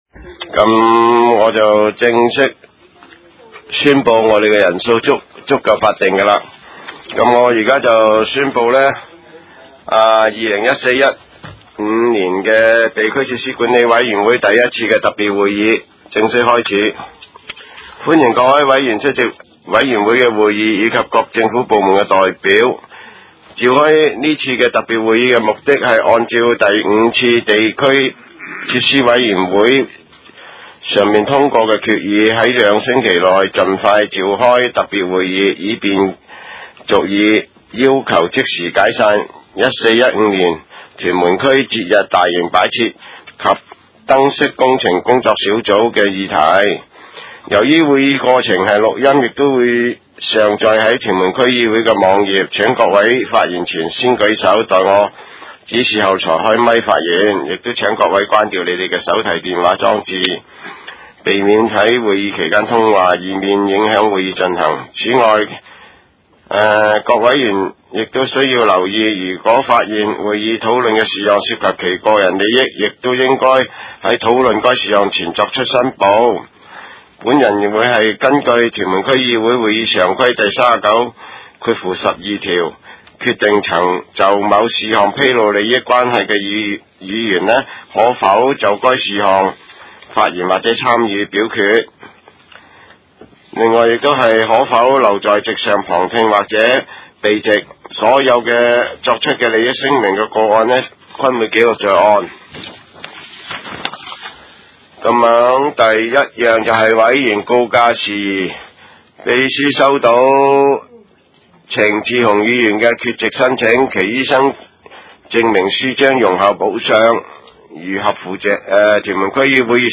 委员会会议的录音记录
地点: 屯门屯喜路1号 屯门政府合署3楼 屯门区议会会议室